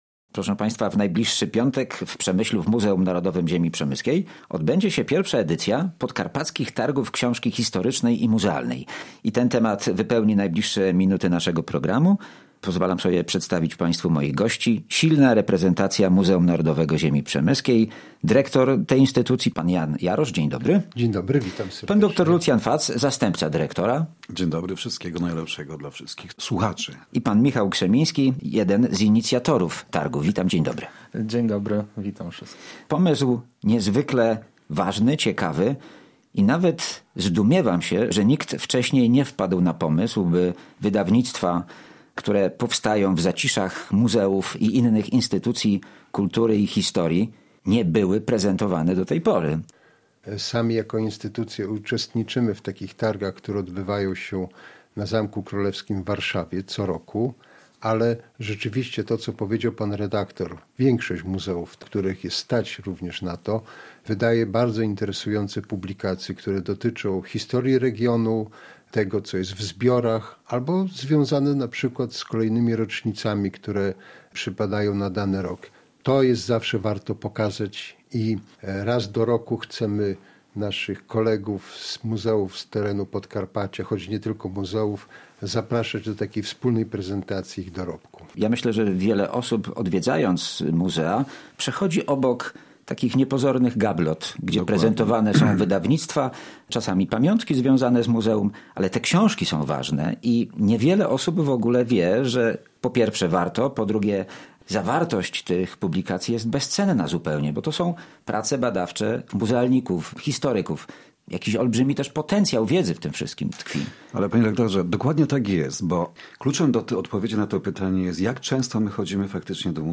O idei targów opowiadali goście audycji radiowej: